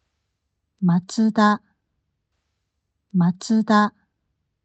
Aber Mazda spricht es ja für uns sogar tasächlich hier als Audio vor
mazda.wav